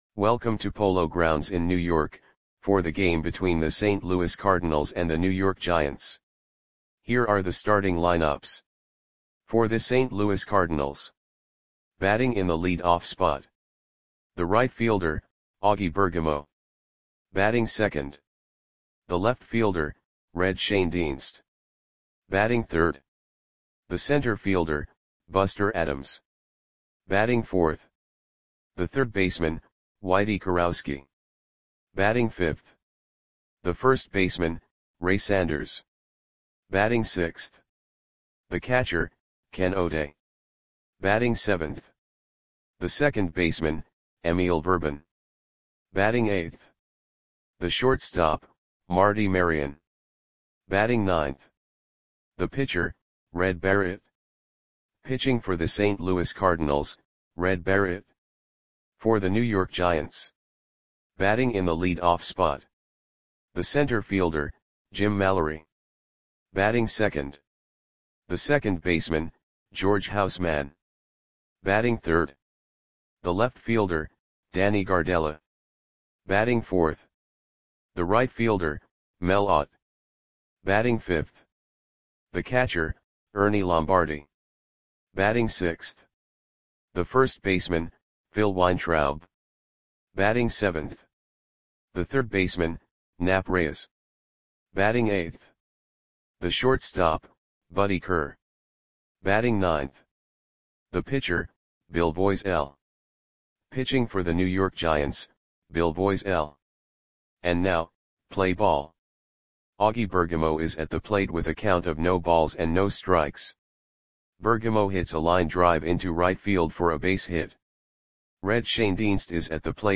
Audio Play-by-Play for New York Giants on July 4, 1945
Click the button below to listen to the audio play-by-play.